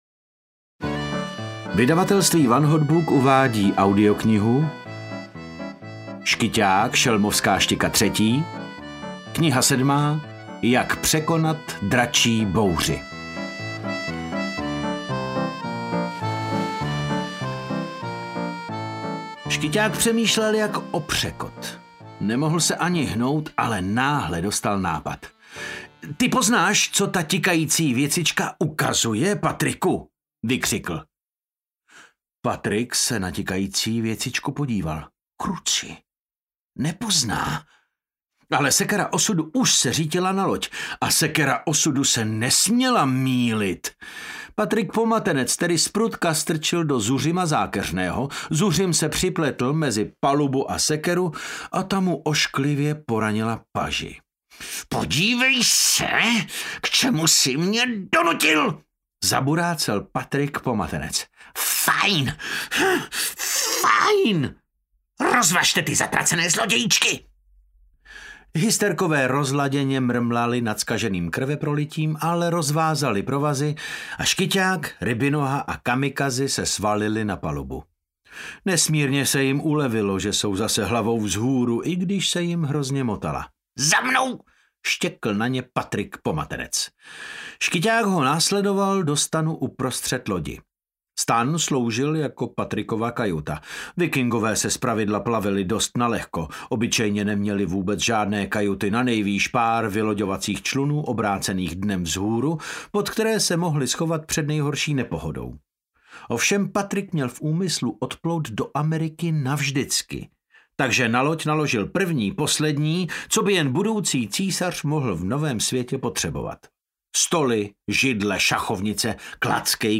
Jak přežít dračí bouři audiokniha
Ukázka z knihy
• InterpretDavid Novotný